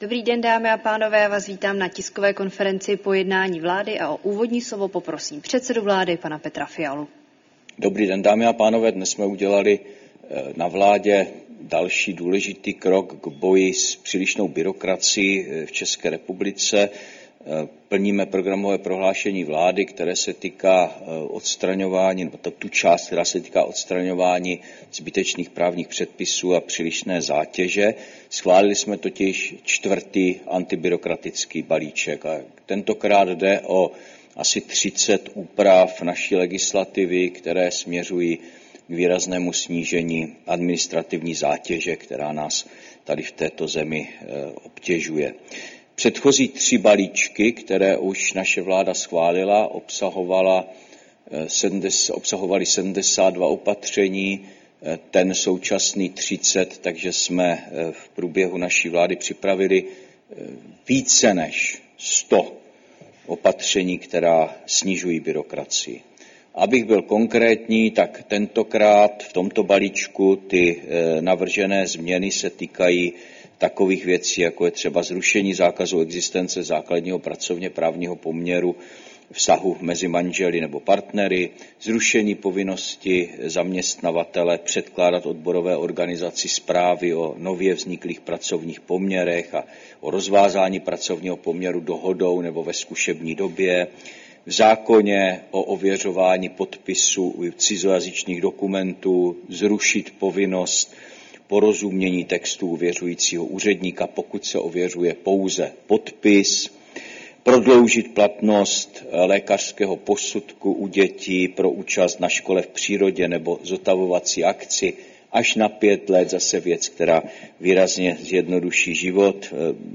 Tisková konference po jednání vlády, 27. srpna 2025